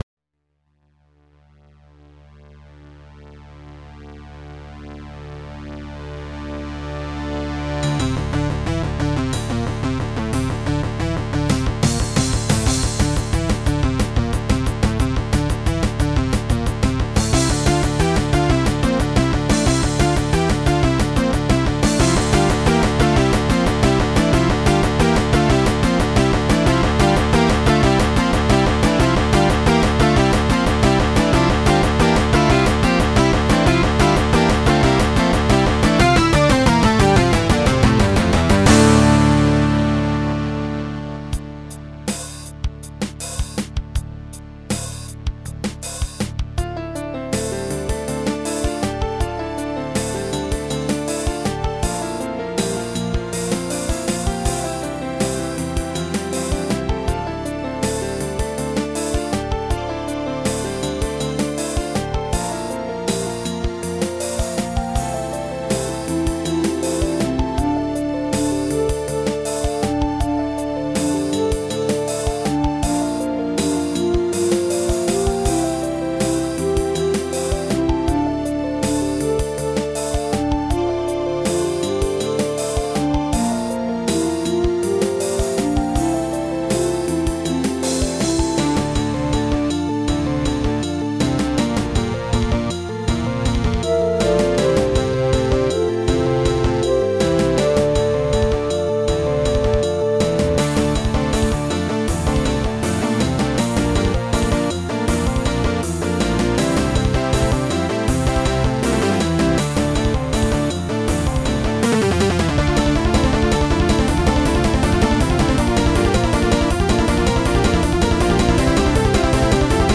A completely insane prog song.